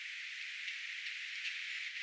kovia selviä tsak-ääniä.
kolme tsak-ääntä peräkkäin
kolme_tsak-aanta_perakkain.mp3